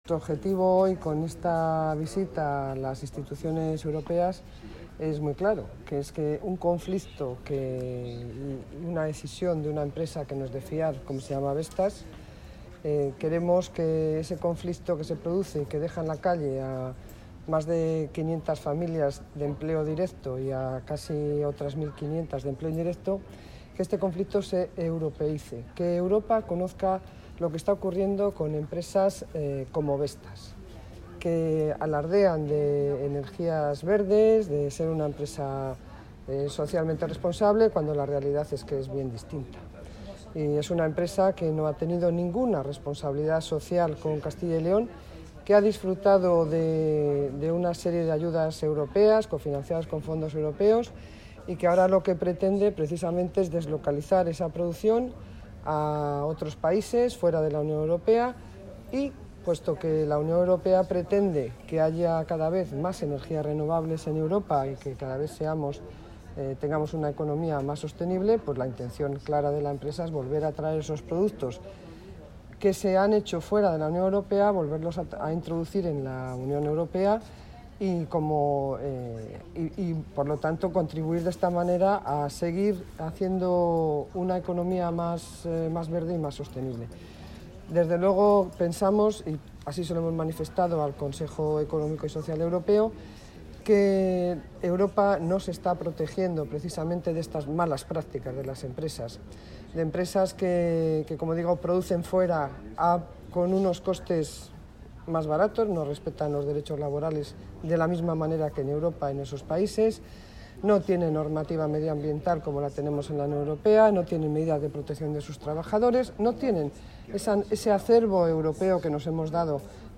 Declaraciones de la consejera de Economía y Hacienda al término de la reunión con la Confederación Europea de Sindicatos sobre Vestas
Declaraciones de la consejera de Economía y Hacienda, Pilar del Olmo, al término de la reunión entre representantes de la Fundación Anclaje y el Comité de Empresa de Vestas con la Confederación Europea de Sindicatos, celebrada en la sede del Comité Económico y Social Europeo (CESE), en Bruselas (Bélgica).